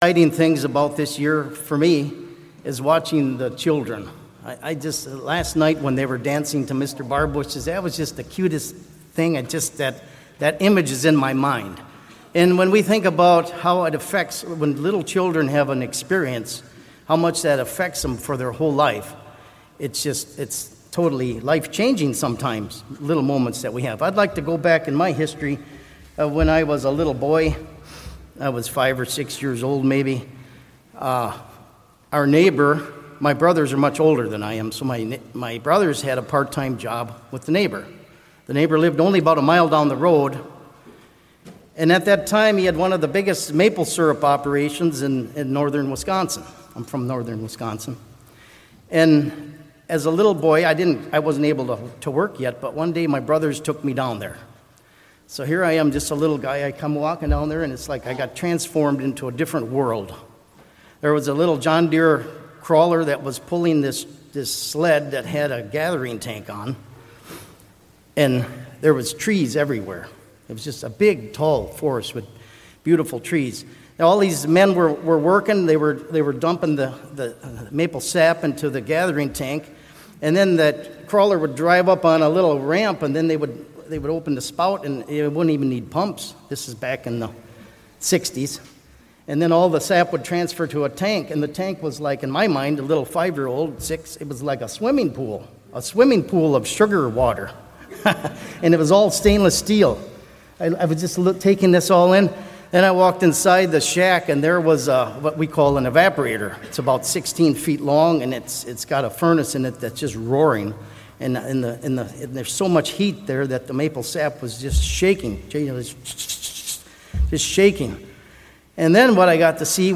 Given in Lake Geneva, Wisconsin